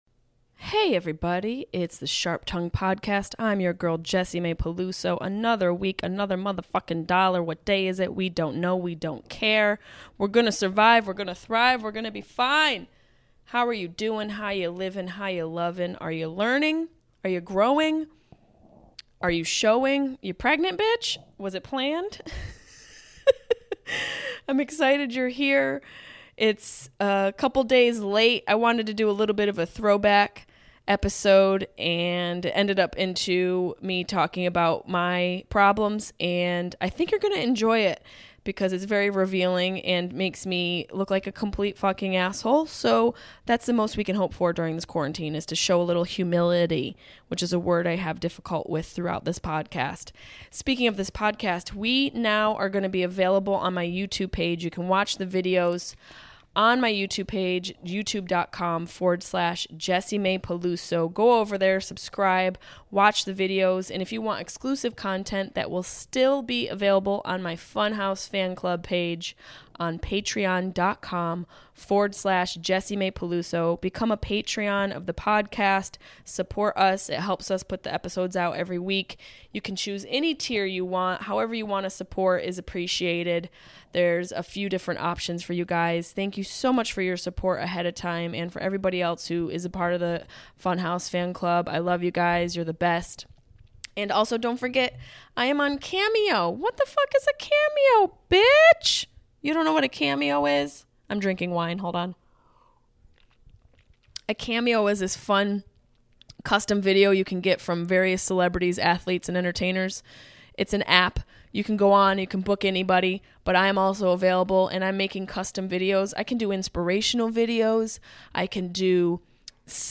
This week, Jessimae discusses how to thrive in quarantine, why Brad Pitt should date her, ways to handle your depression, and how to keep moving forward in these difficult times. We also play a few fan voicemails.